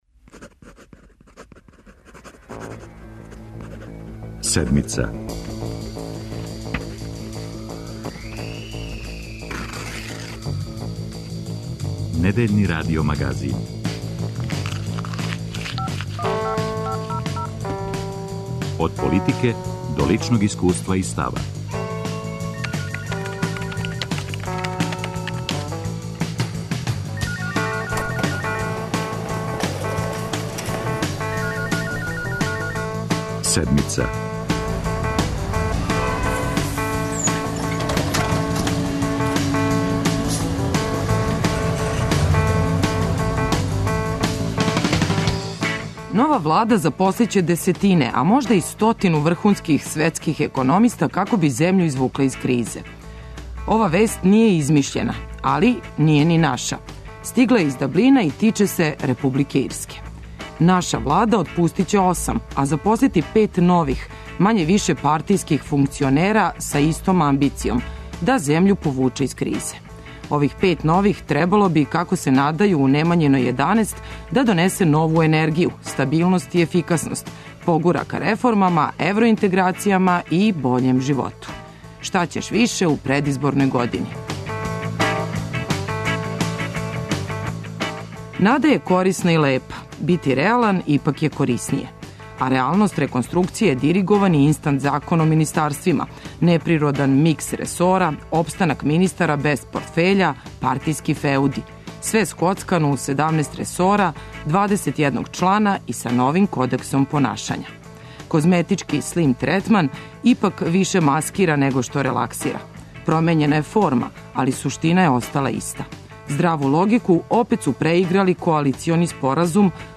Радио Београд 1